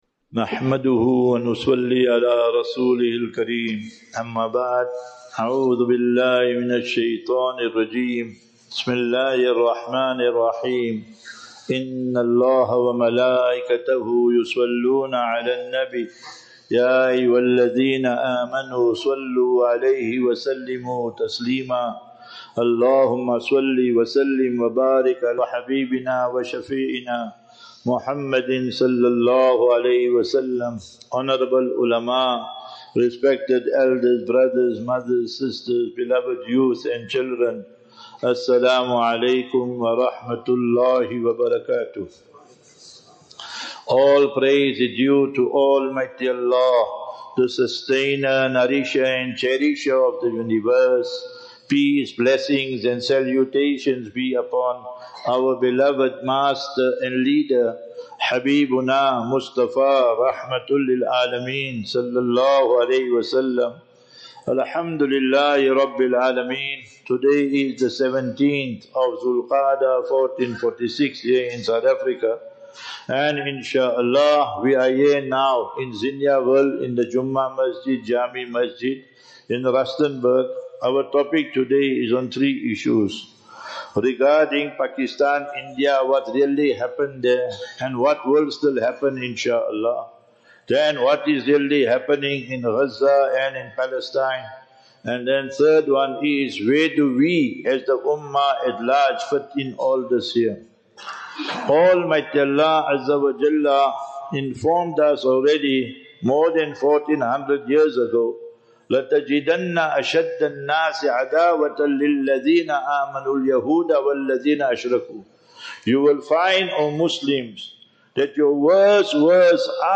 16 May 16 May 2025 - Jumu'ah Lecture in Jaame Masjid Zinniaville - Rustenburg